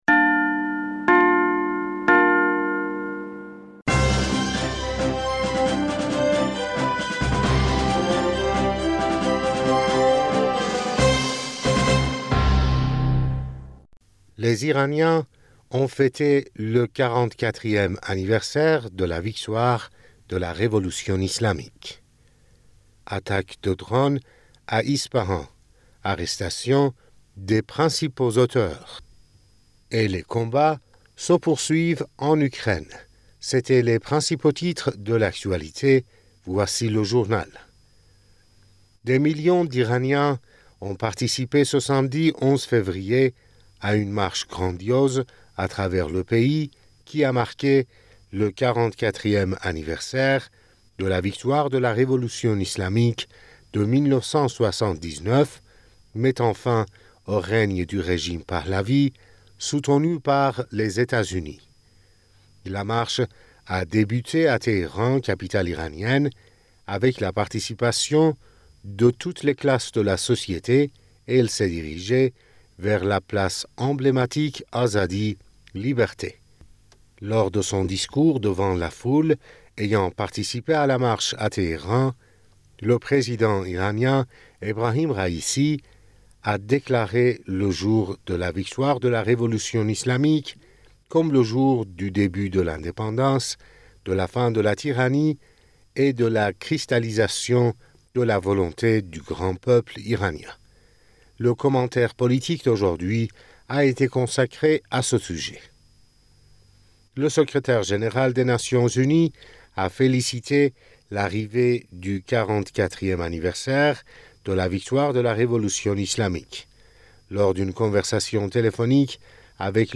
Bulletin d'information du 11 Février